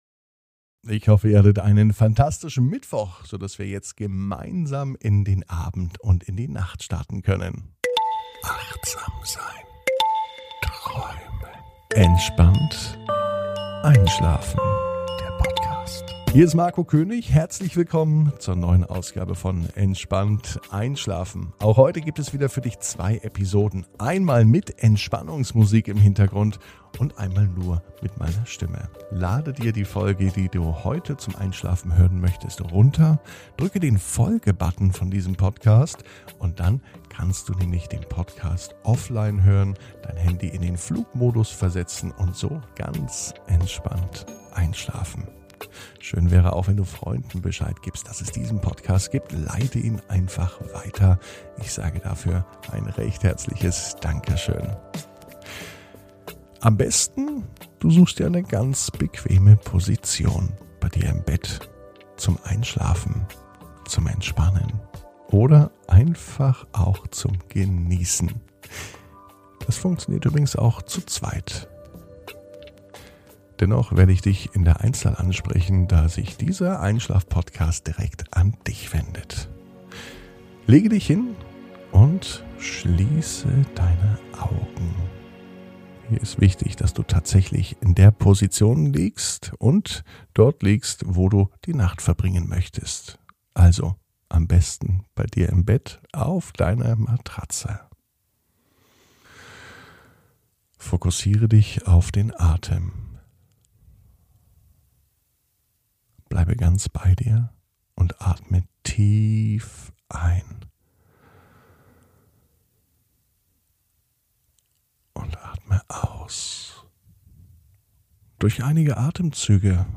(ohne Musik) Entspannt einschlafen am Mittwoch, 21.04.21 ~ Entspannt einschlafen - Meditation & Achtsamkeit für die Nacht Podcast